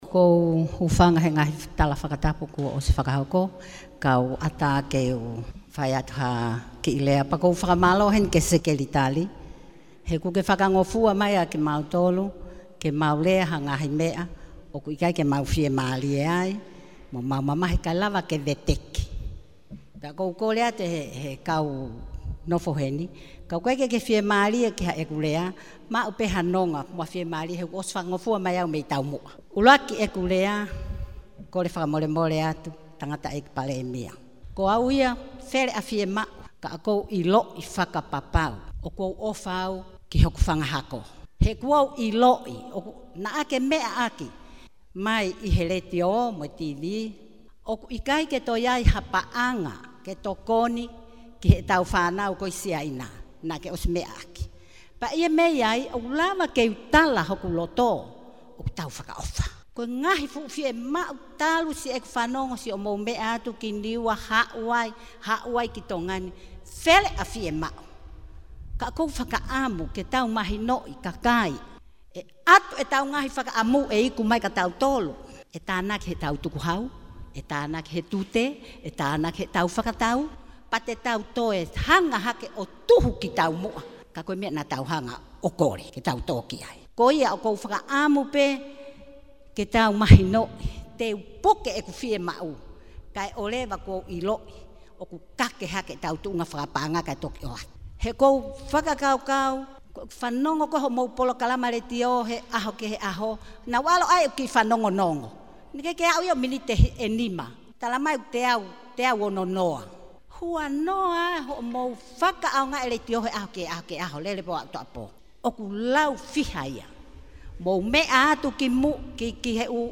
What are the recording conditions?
Audios of the Prime Minister’s meeting in Tongatapu 2 were provided by the Prime Minister’s office and transcribed and translated into English by Kaniva News.